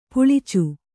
♪ puḷicu